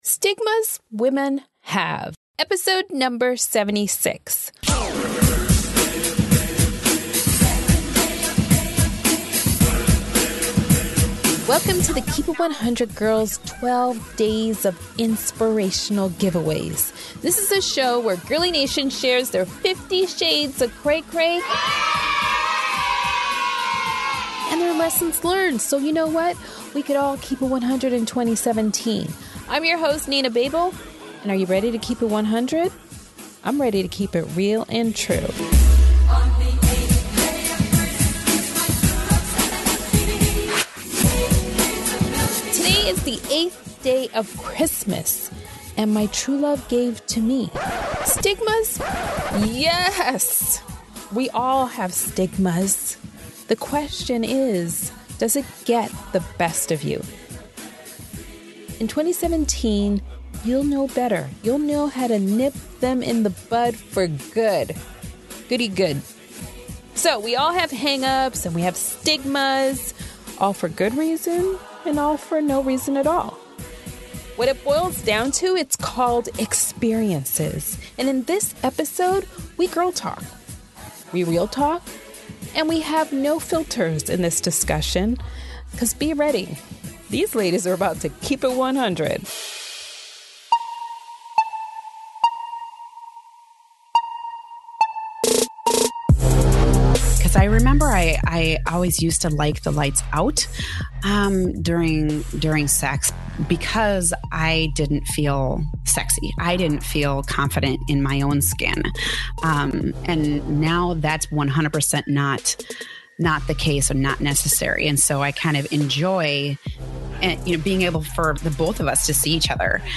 Women share their vulnerable moments in their lives.